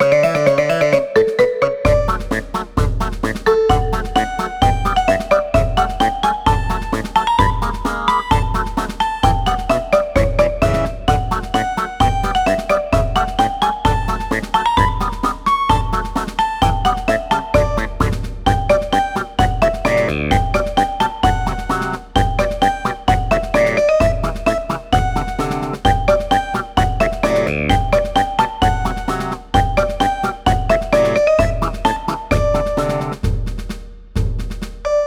【イメージ】かわいい、おいかけっこ など